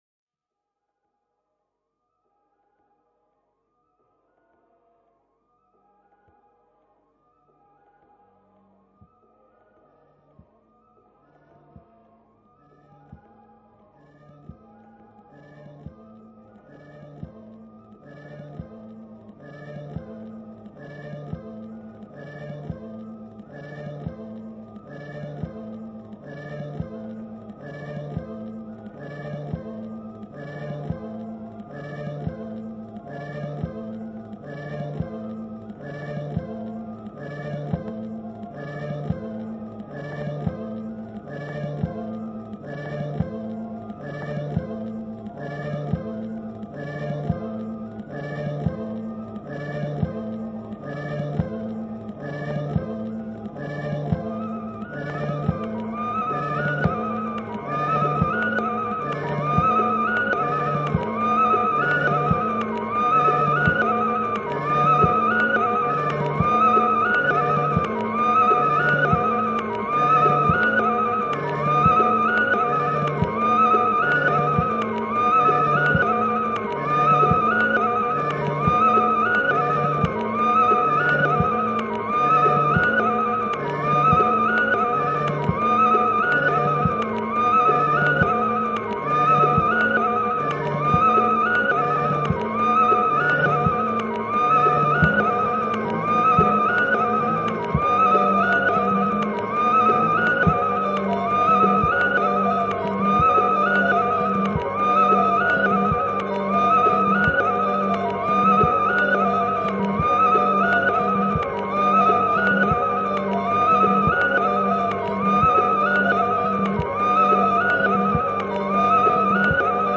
operatic concert sound installation